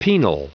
Prononciation du mot penal en anglais (fichier audio)
Prononciation du mot : penal